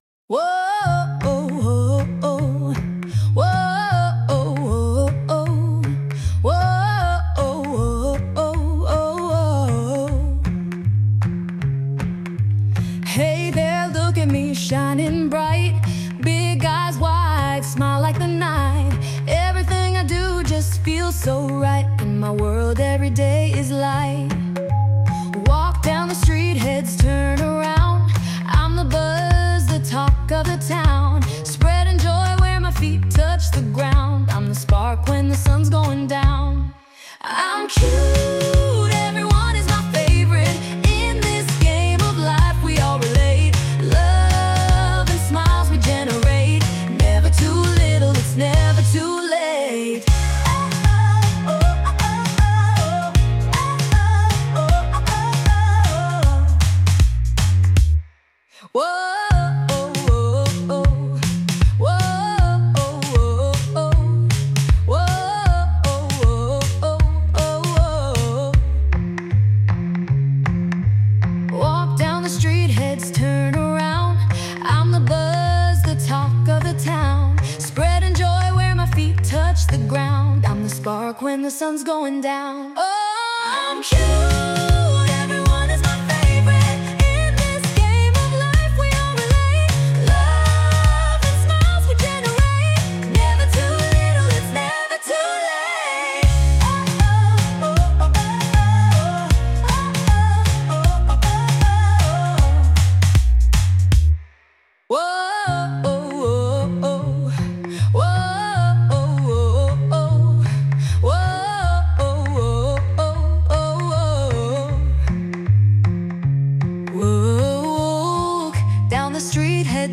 甘い歌声の女性ボーカルと、誰もが自然とリズムに乗れる踊りやすいテンポが特徴です。
聴いていると思わず手拍子したくなるようなキャッチーなメロディラインは、会場全体を味方につける強力な武器になります。